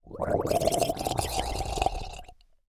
assets / minecraft / sounds / mob / drowned / idle4.ogg